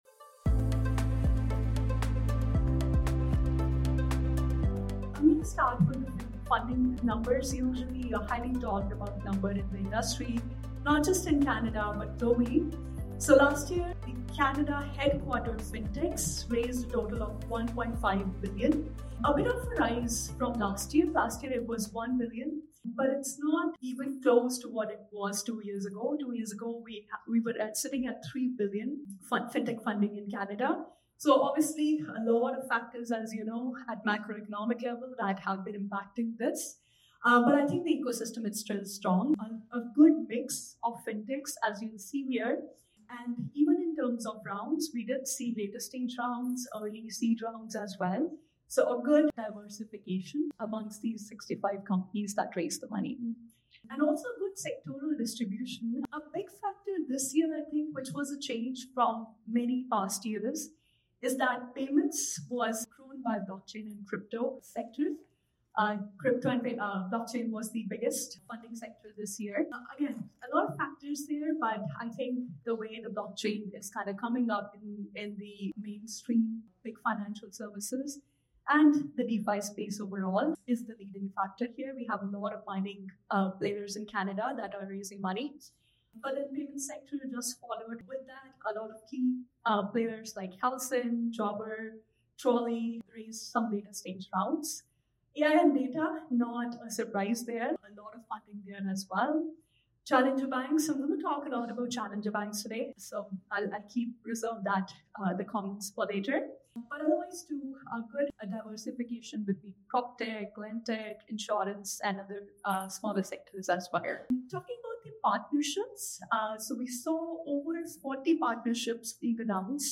Fathom4sight at 2025 Payments Canada Summit | FinTech Trends by Fathom FinTech